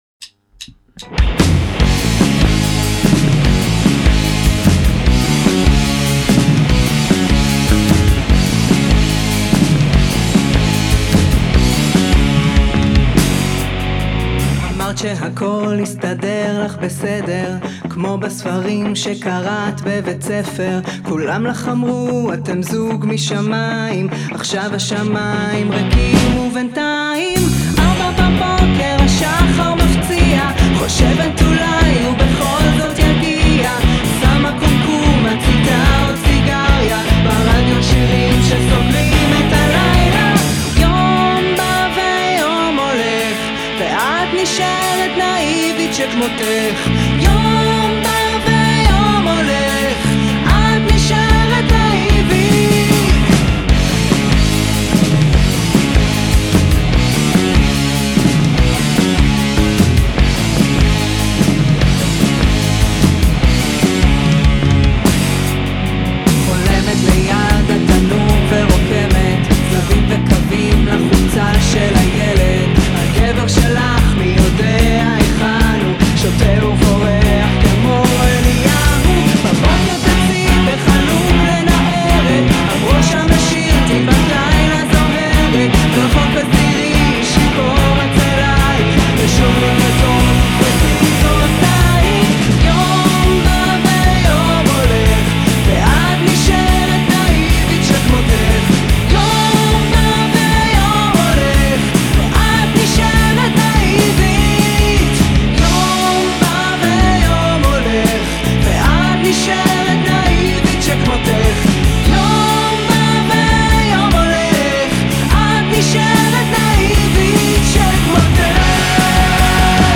רוקנרול כמו שצריך להיות!